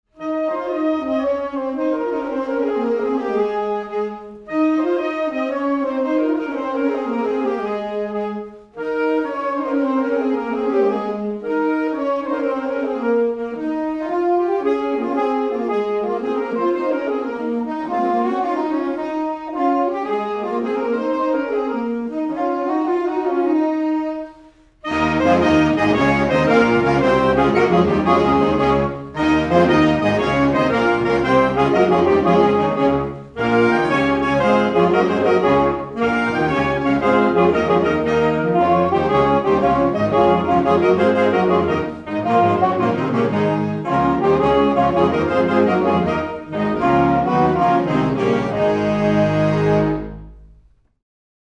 The bass saxophone is the heart and soul of a large saxophone ensemble.
this mp3 clip (2.0MB) of the San Diego Saxophone Orchestra playing Percy Grainger's Annunciation Carol. The clip starts without the bass, but you'll know when it comes in!